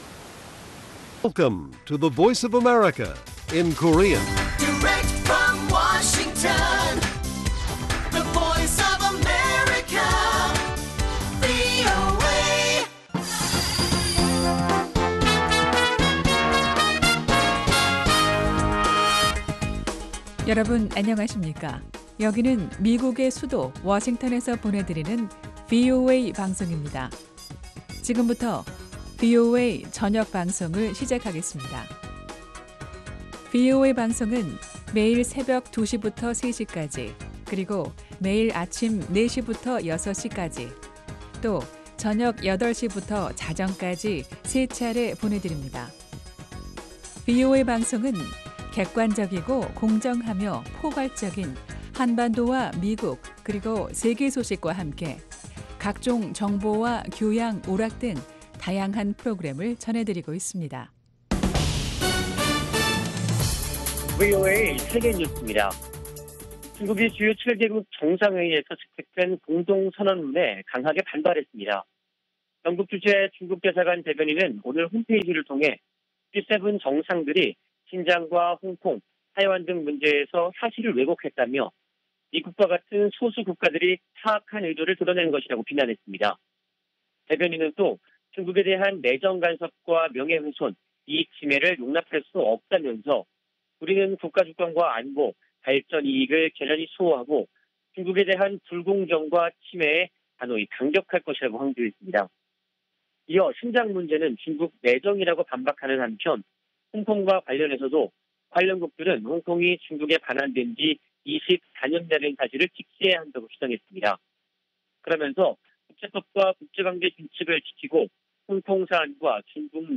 VOA 한국어 간판 뉴스 프로그램 '뉴스 투데이', 2021년 6월 14일 1부 방송입니다. 미국과 독일 등 주요 7개국(G7) 정상들이 공동성명을 채택하고 한반도의 완전한 비핵화·대북 제재 이행·대화 재개를 촉구했습니다. 북한 핵 문제에 대한 바이든 행정부와 미 의회의 관심이 뜸해진 양상을 보이고 있는 가운데, 전문가들은 북한이 적극성을 보이지 않으면 이런 기류가 계속될 것으로 전망했습니다.